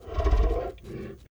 PixelPerfectionCE/assets/minecraft/sounds/mob/guardian/land_idle4.ogg at mc116
land_idle4.ogg